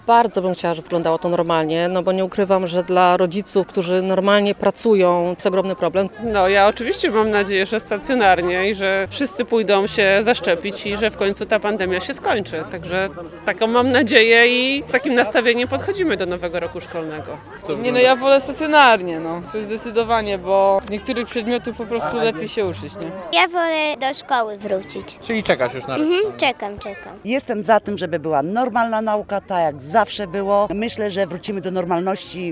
[SONDA]
Zapytaliśmy zielonogórzan – Jak, ich zdaniem, będzie wyglądał rok szkolny 2021/22?